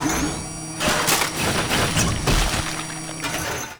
Trash collection
Crunch.wav